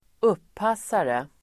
Ladda ner uttalet
Folkets service: uppassare uppassare substantiv, waiter Uttal: [²'up:as:are] Böjningar: uppassaren, uppassare, uppassarna Synonymer: betjänt, kypare Definition: betjänt, servitör (steward) jack substantiv, uppassare